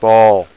BALL.mp3